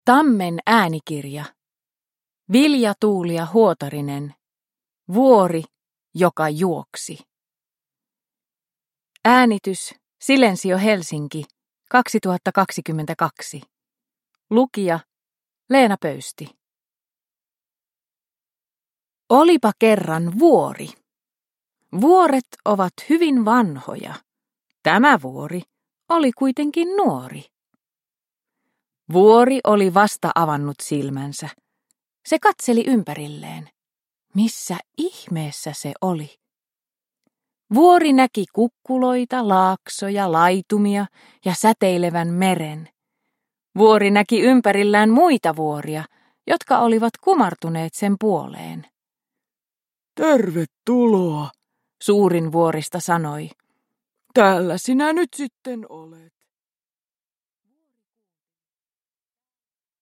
Vuori, joka juoksi – Ljudbok – Laddas ner